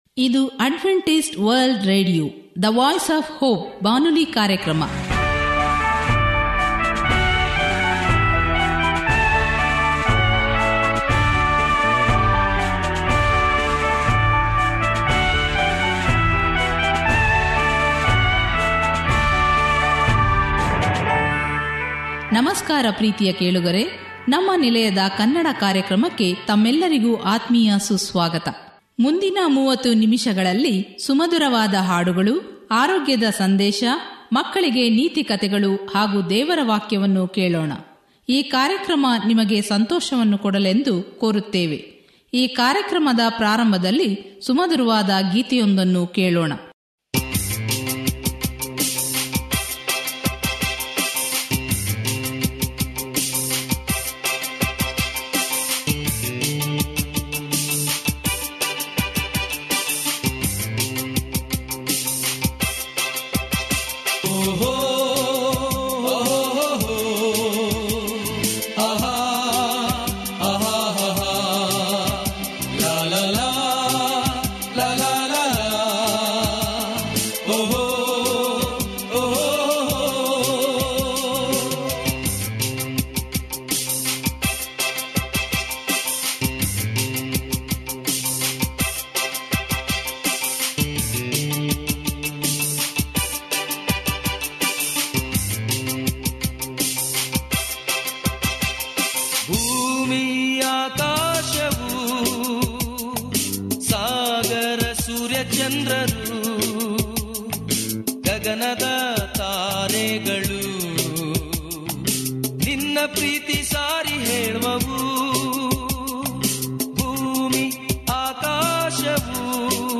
Radio programs in Kannada / ಕನ್ನಡ / Kannaḍa for Karnataka, Kerala, Maharashtra, Andhra Pradesh, Goa, India, by Adventist World Radio